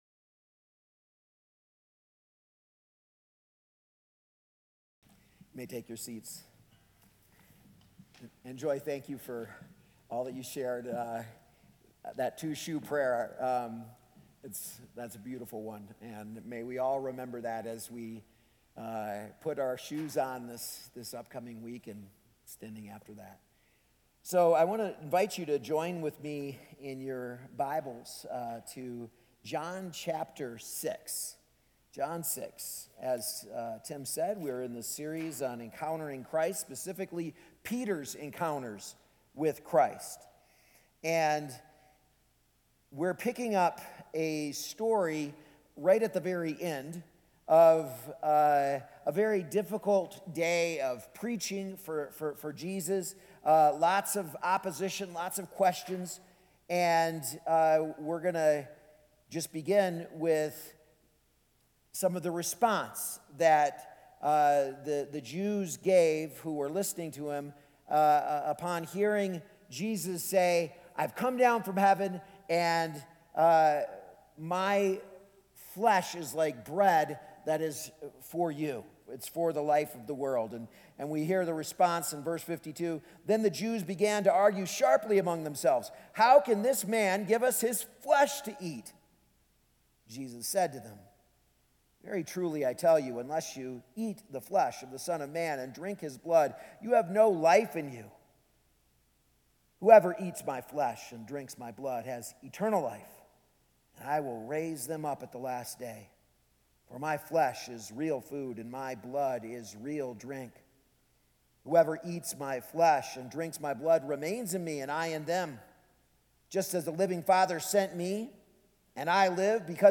A message from the series "Encountering Christ."